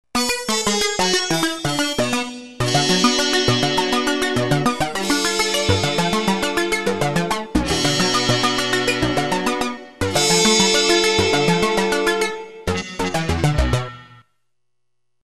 arpsono trumpet.mp3